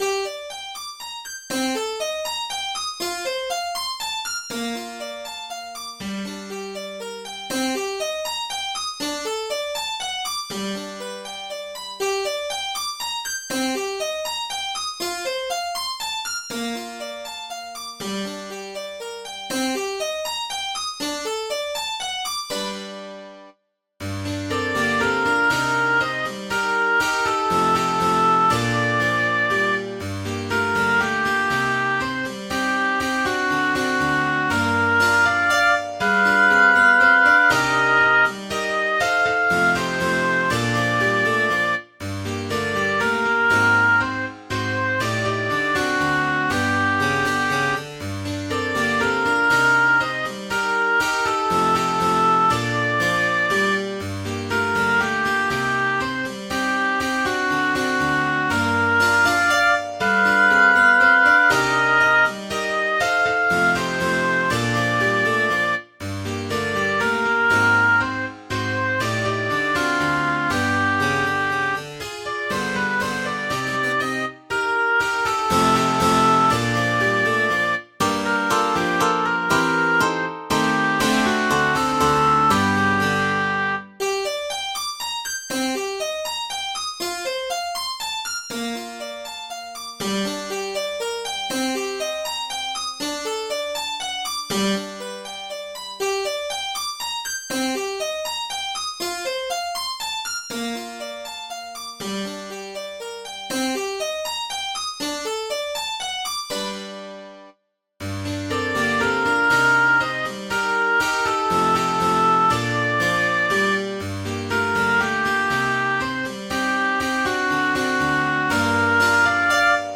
navidad nuestra - 2 la peregrinacion-s.mp3